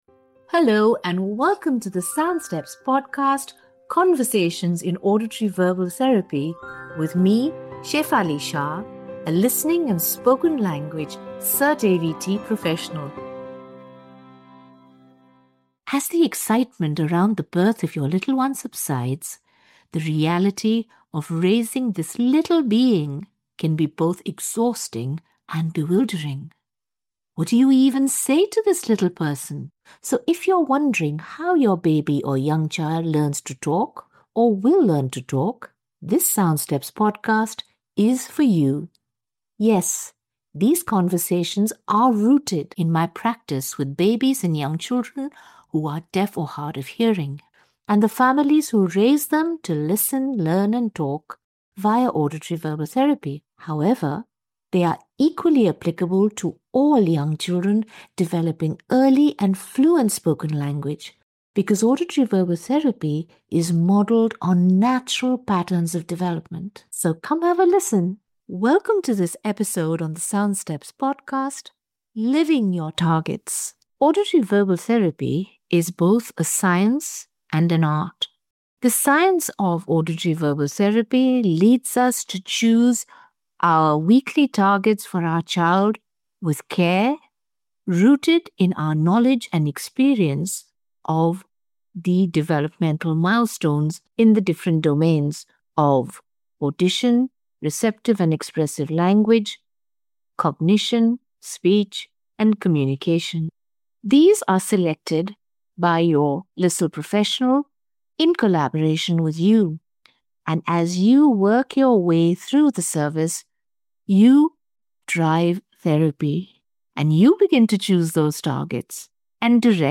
Conversations in Auditory-Verbal Therapy